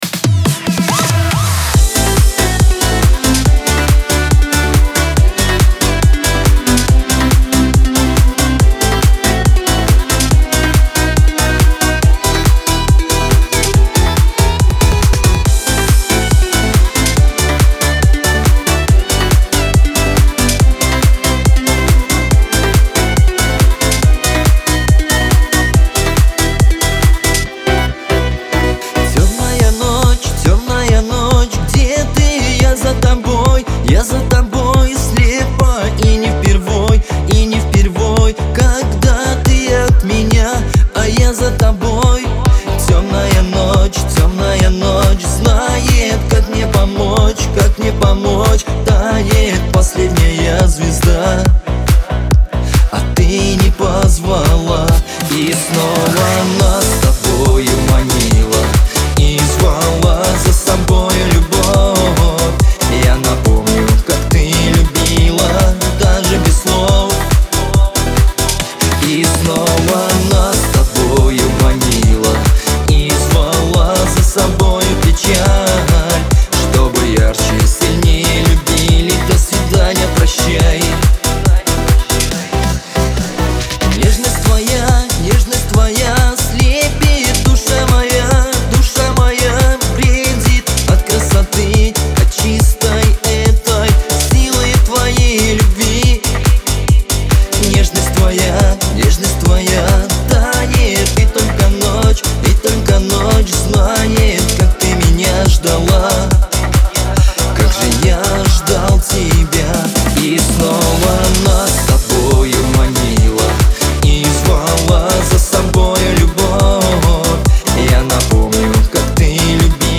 Лирика
грусть , Кавказ – поп